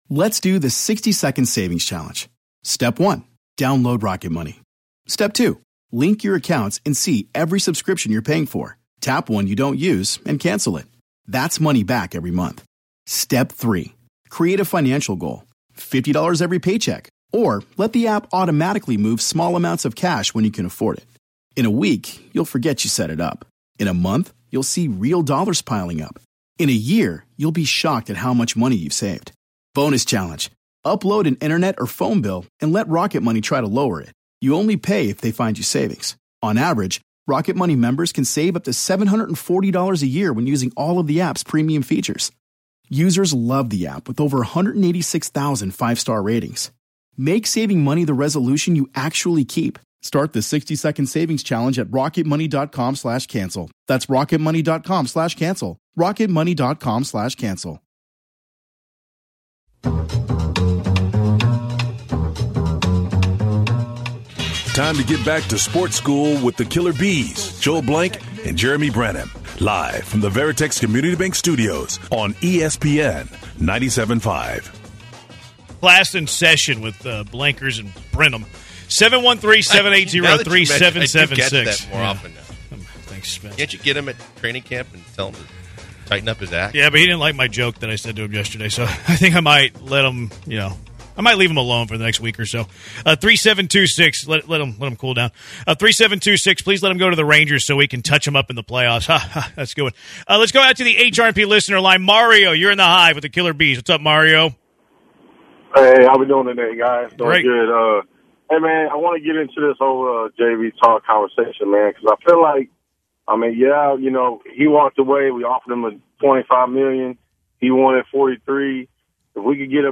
The Justin Verlander trade talks continue with more people calling in with their thoughts.